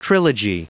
Prononciation du mot trilogy en anglais (fichier audio)
Prononciation du mot : trilogy